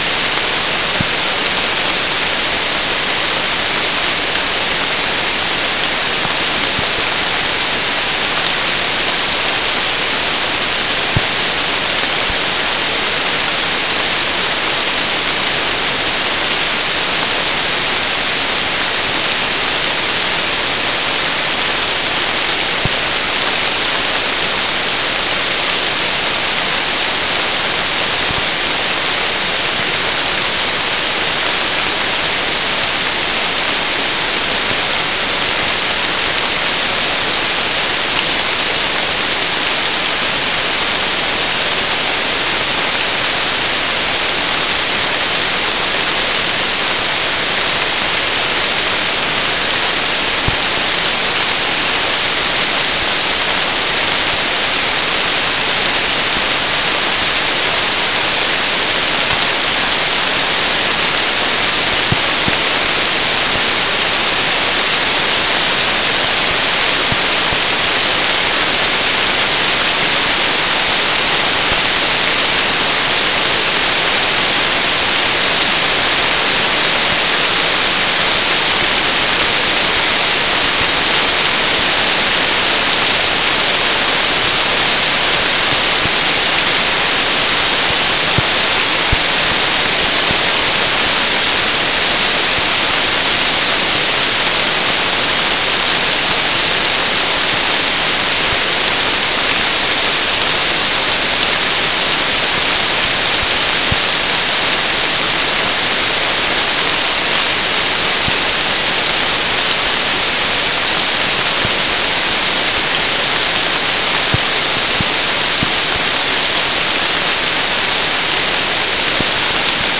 Soundscape Recording Location: South America: Guyana: Turtle Mountain: 3
Recorder: SM3